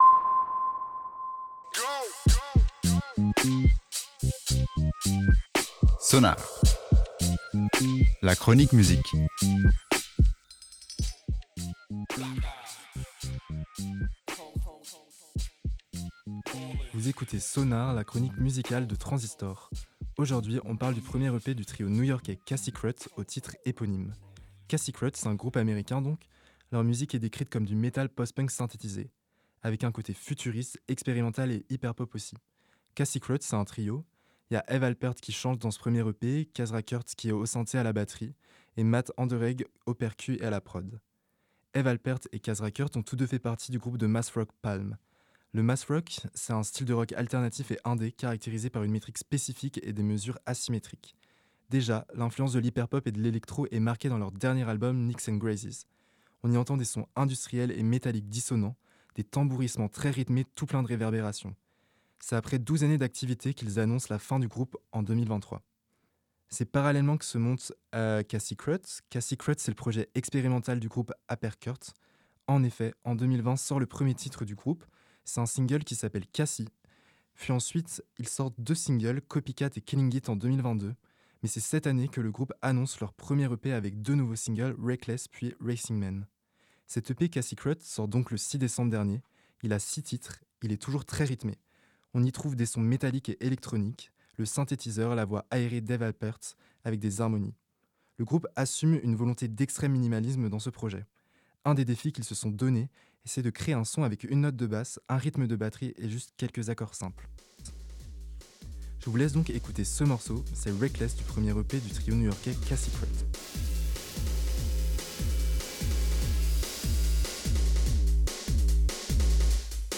Il s'agit d'un disque expérimental
une basse rythmée et répétitive
Le projet se veut minimaliste.